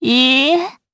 DarkFrey-Vox-Deny_jp.wav